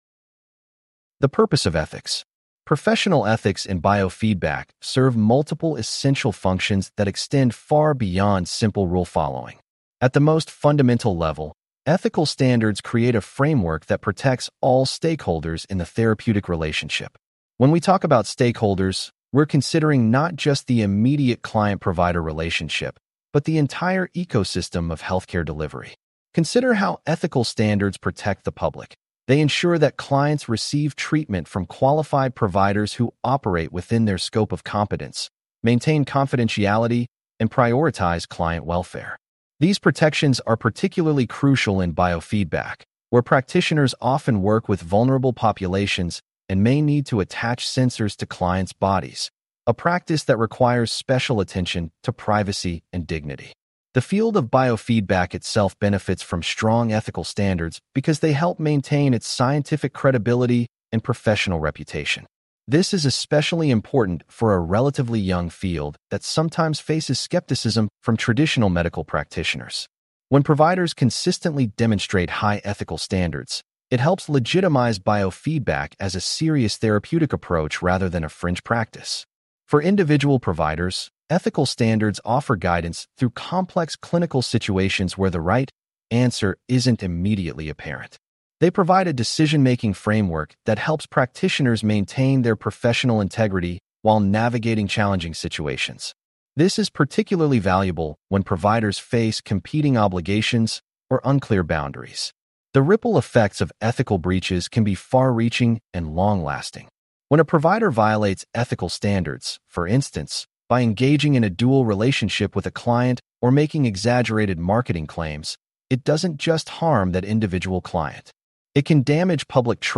Please click on the podcast icon below to hear a full-length lecture.